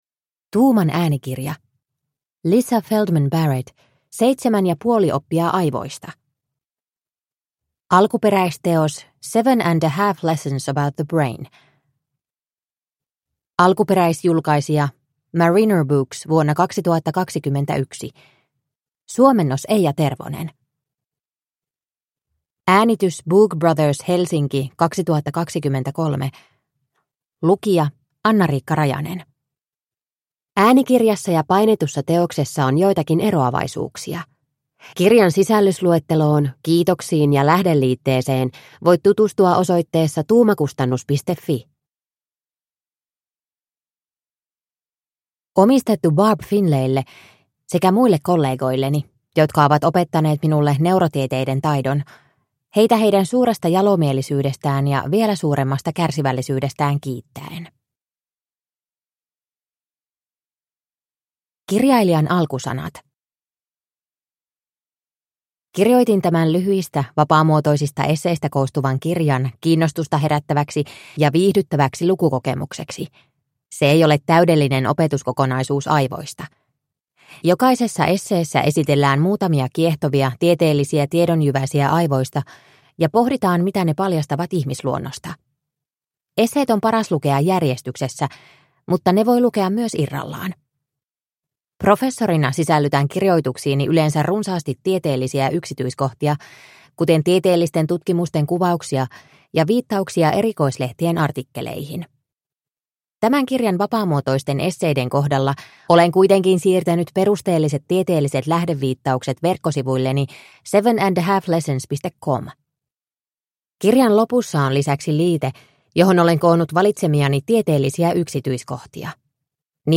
Seitsemän ja puoli oppia aivoista – Ljudbok – Laddas ner